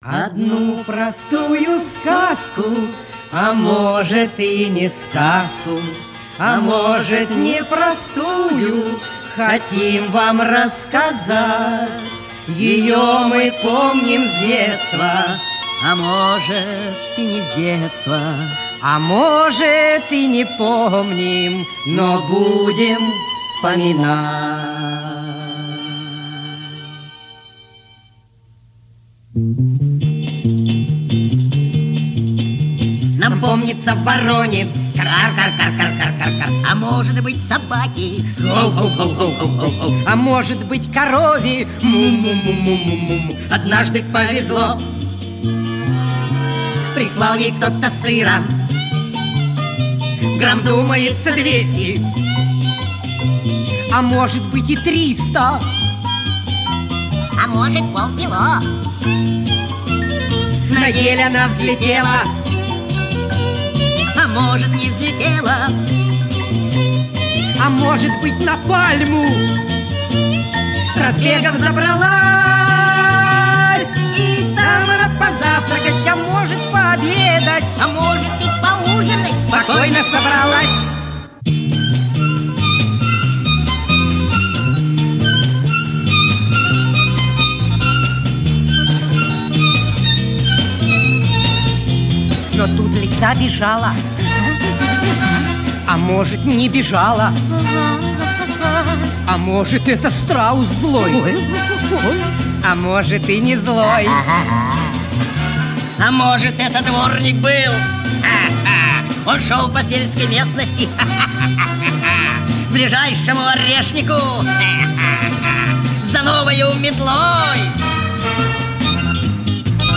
песенка-небылица